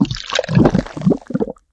alien_points_received.wav